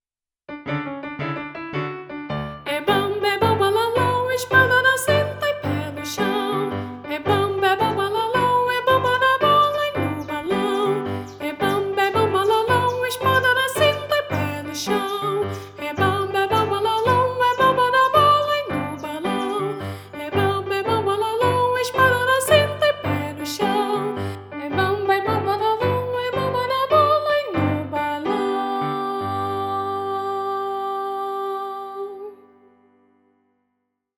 Voz Guia